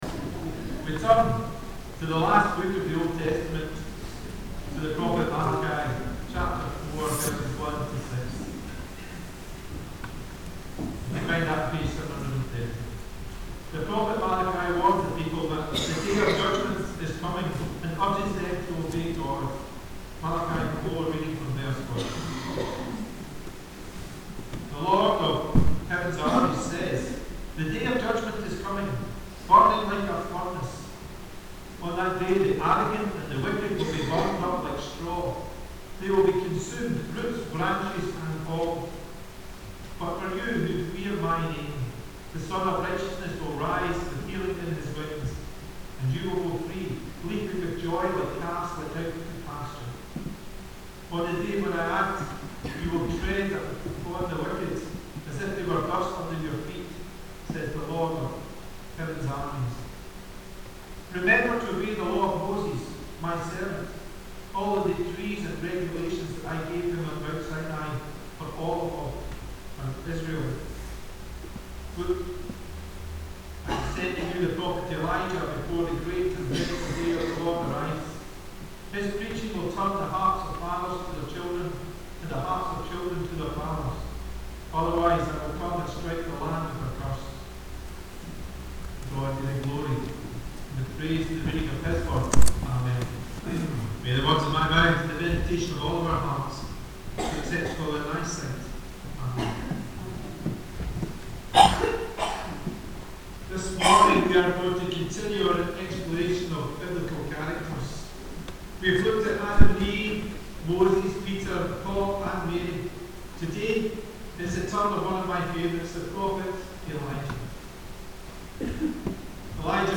The Scripture Readings prior to the Sermon are 1 Kings 17: 1-16 and Malachi 4: 1-6